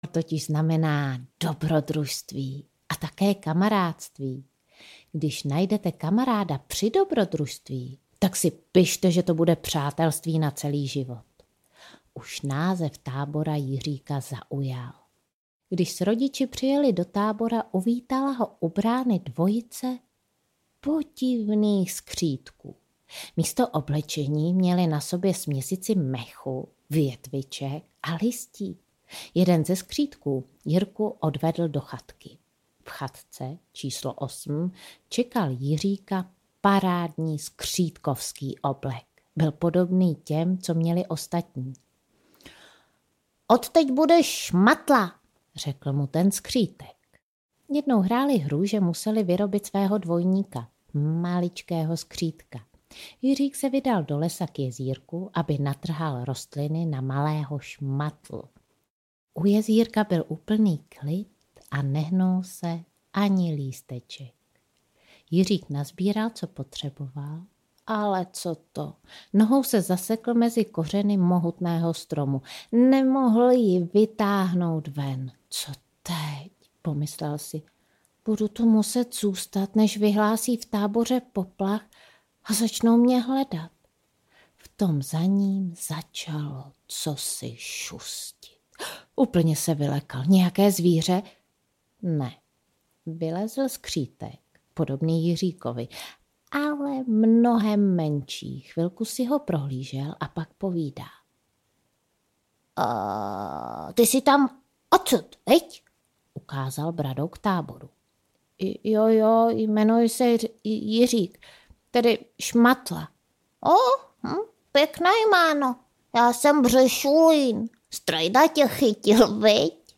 Davidovy pohádky (nejen) na čištění zoubků audiokniha
Ukázka z knihy